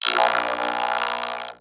robotscream_4.ogg